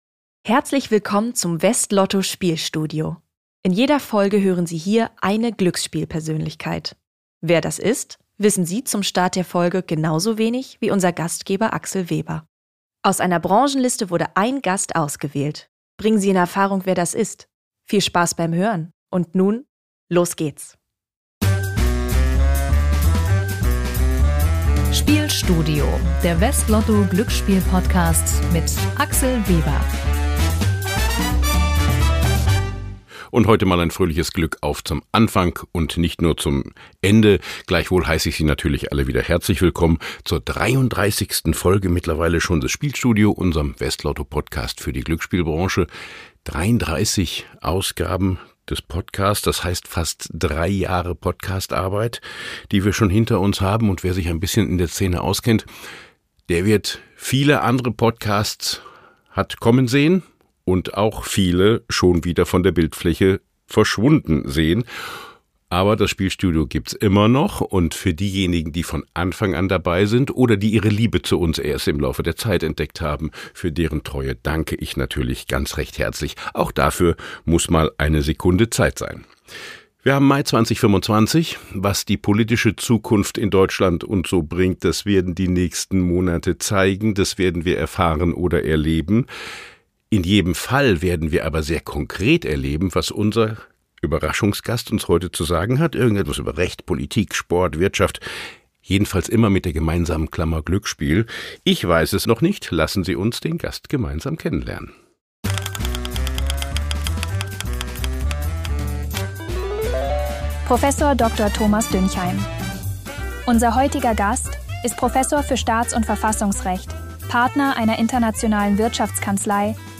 Warum diskutieren wir im klassischen Glücksspiel so intensiv Regulierung – und die Kollegen aus dem Gaming-Bereich nicht?“ Es erwartet Sie eine spannende Diskussion mit wertvollen Impulsen. Hinweis: Diese Folge enthält einen Zuspieler, der von einer KI-Stimme gesprochen wird.